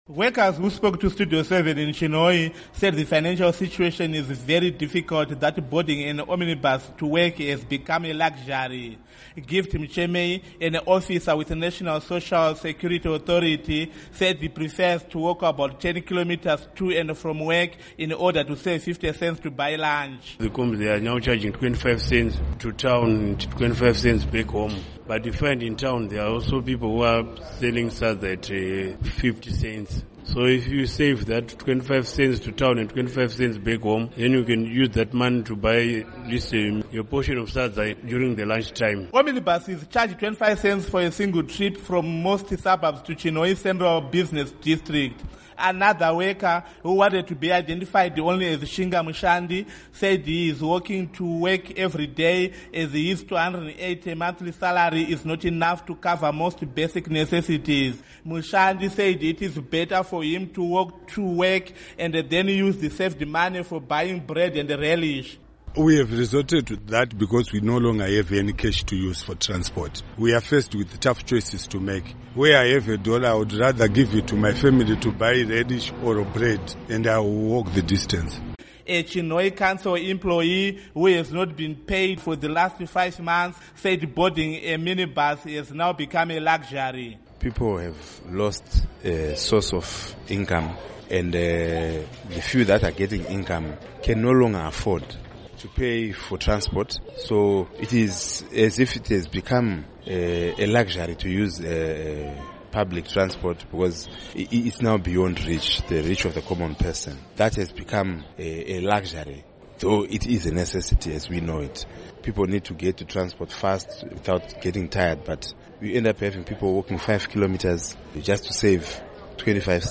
Workers, who spoke to Studio 7 in Chinhoyi, said the financial situation is very difficult that boarding an omnibus to work has become a luxury.